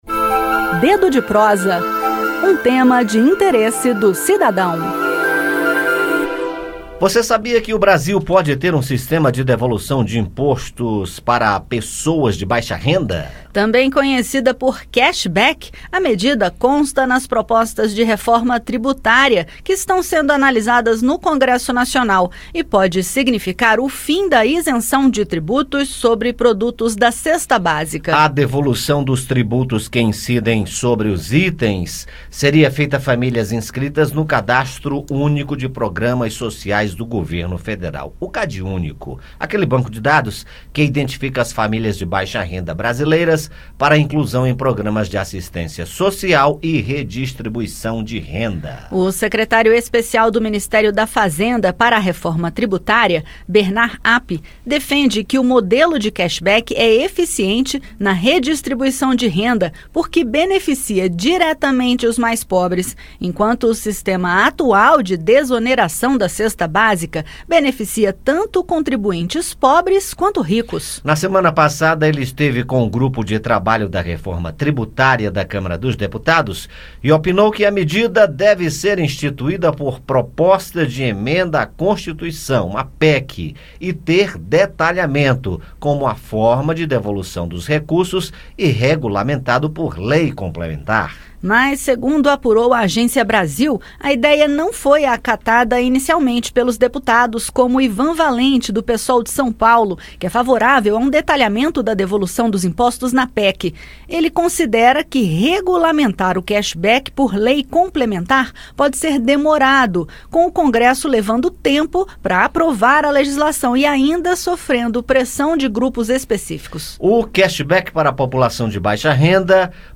No bate-papo, entenda a proposta, a discussão entre arrecadação e retorno dos recursos e saiba como pressionar pelo melhor uso do dinheiro dos impostos.